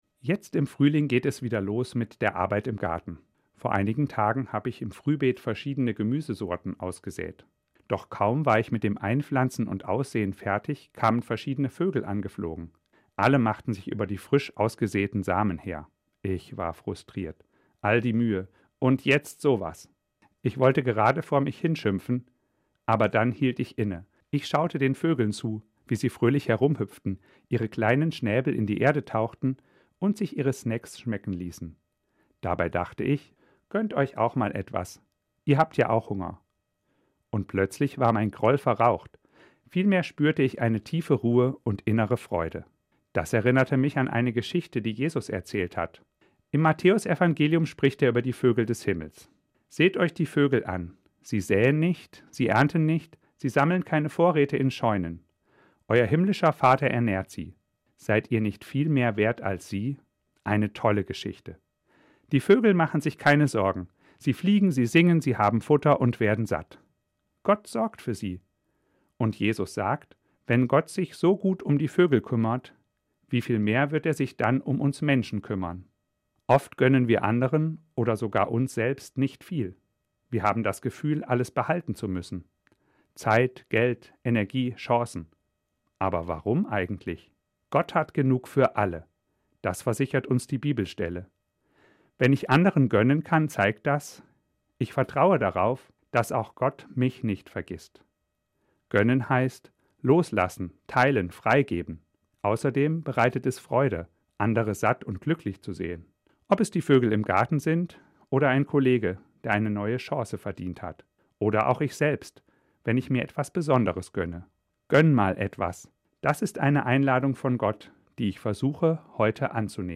Katholischer Pastoralreferent und Religionslehrer, Fulda